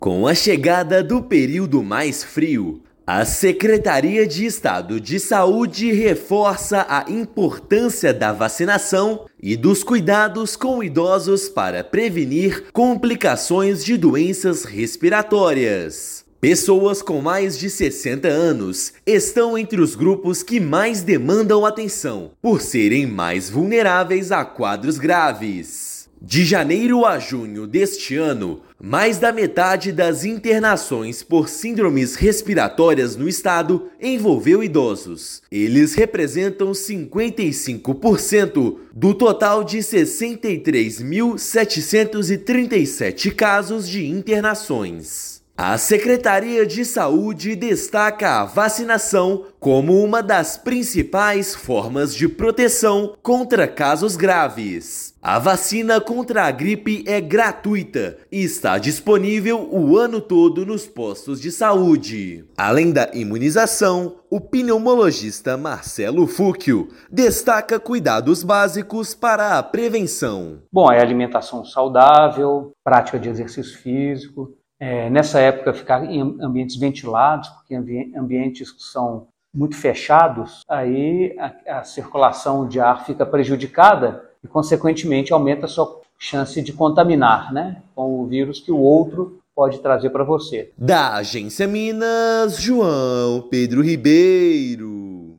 Em 2025, mais de 50% das internações por síndromes respiratórias no estado envolveram pessoas com mais de 60 anos. Ouça matéria de rádio.